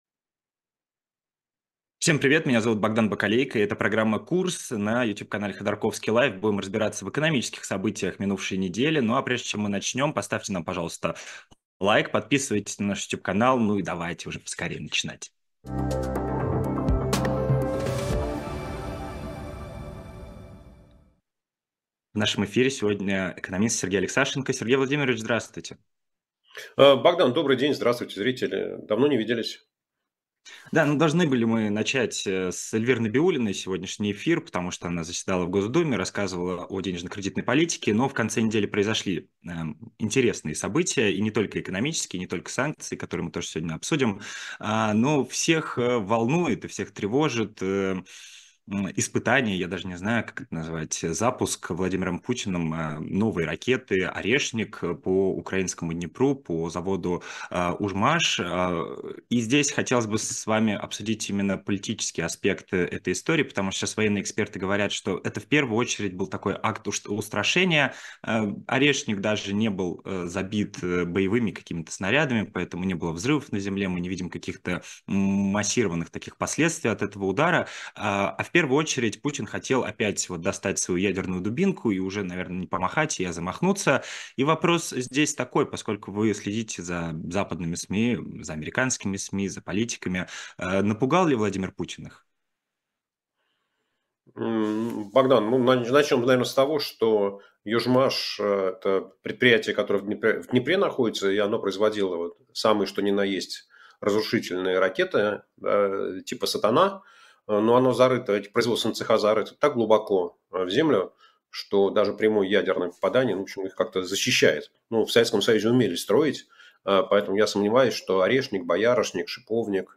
Америка — Россия: окно возможностей» на сайте «Эхо Книги» Сергей Алексашенко разбирает последние новости экономики в программе «Курс». США ввели санкции против десятков российских банков, в том числе и «Газпромбанка» — почему это ударит по курсу рубля? 103 рубля за доллар — это далеко не предел.